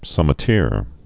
(sŭmĭ-tîr)